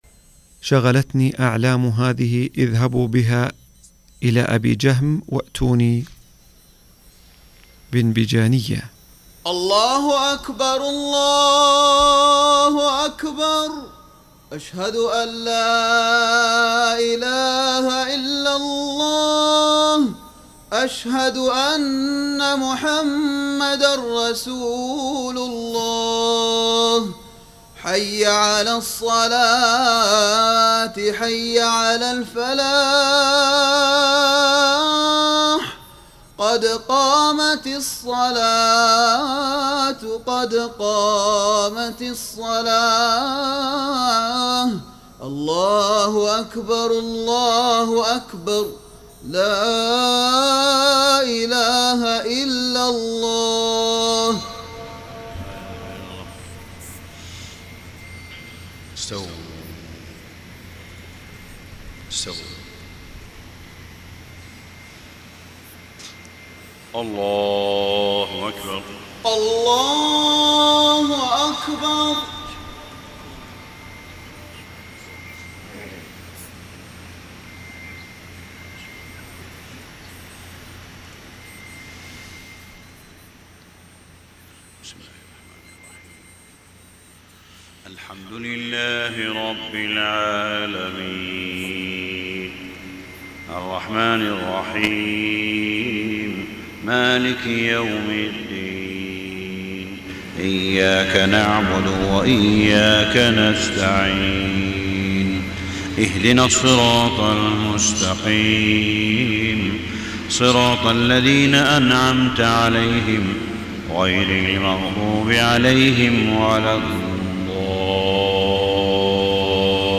صلاة الفجر 9-9-1434 من سورة التحريم > 1434 🕋 > الفروض - تلاوات الحرمين